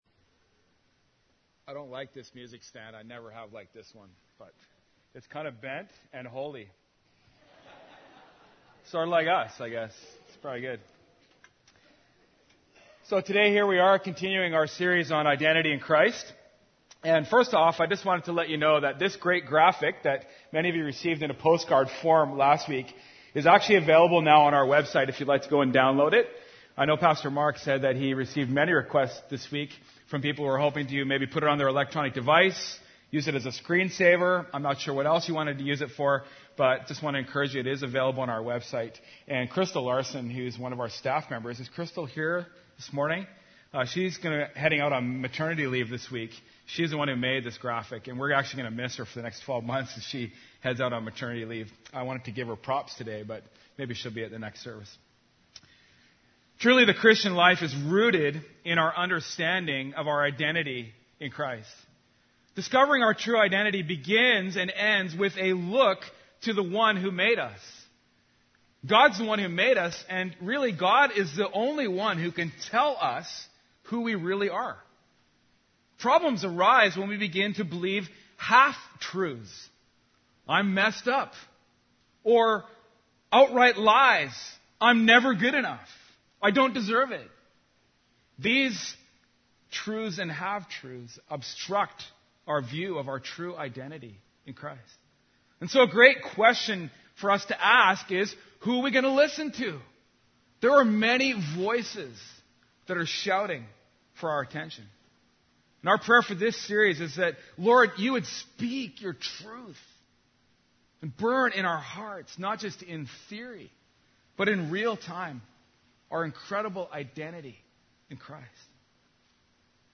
Sermons | North Shore Alliance Church